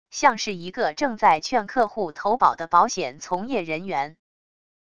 像是一个正在劝客户投保的保险从业人员wav音频